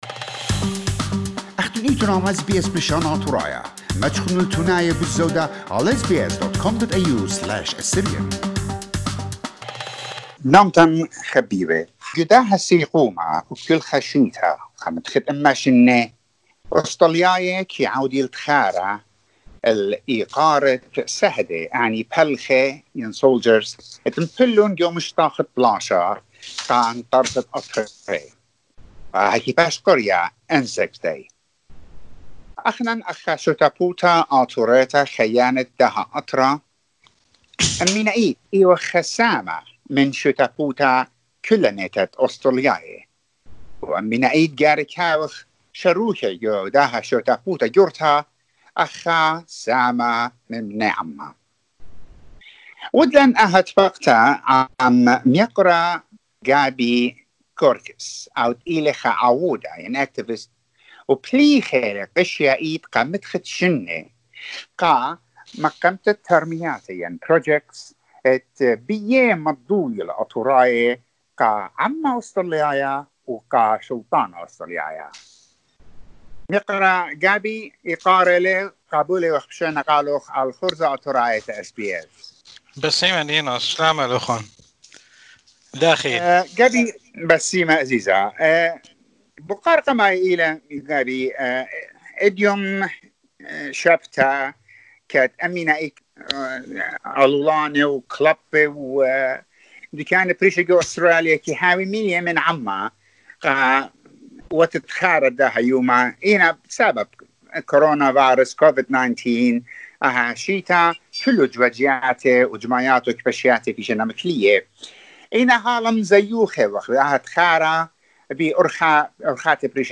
In an interview with SBS Assyrian